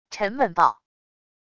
沉闷爆wav音频